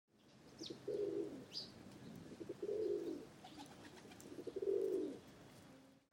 جلوه های صوتی
دانلود صدای پرنده 25 از ساعد نیوز با لینک مستقیم و کیفیت بالا